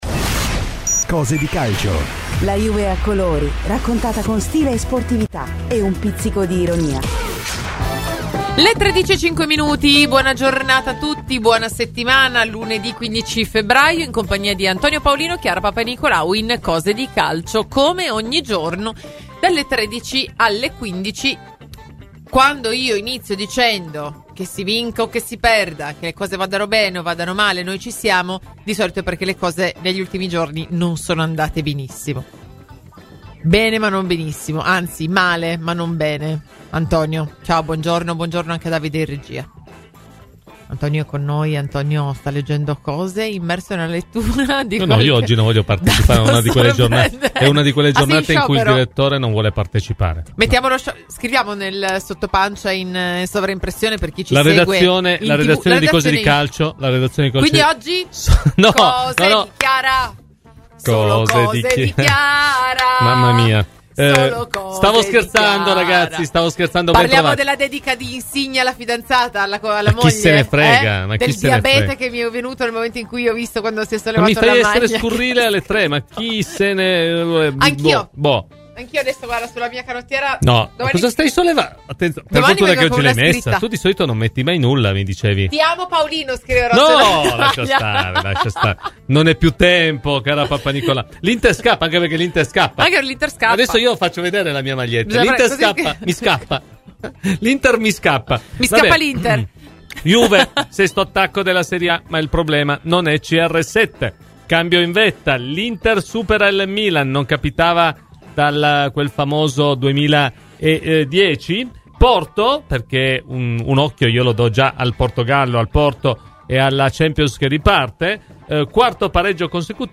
L'ex Juventus Cristian Zenoni ha parlato ai microfoni di Radio Bianconera, nel corso di 'Up&Down': "Il rigore per il Napoli?